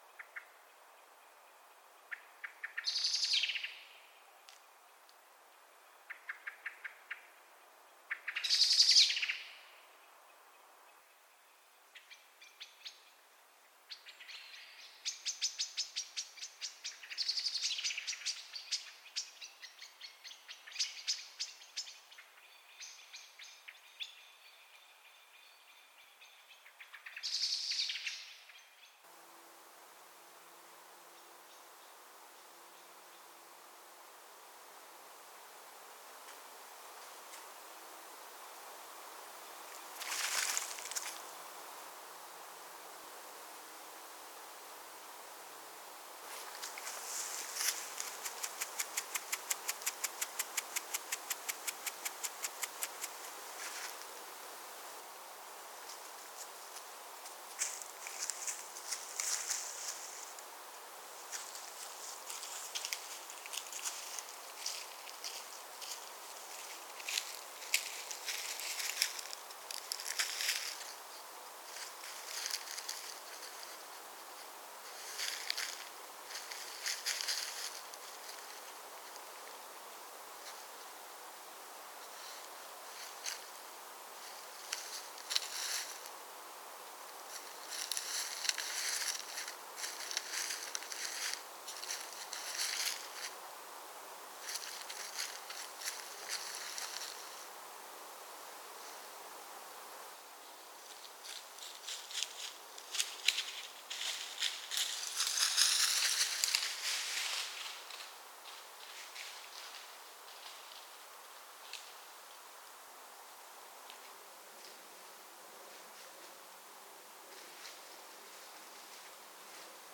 Dachs_Audio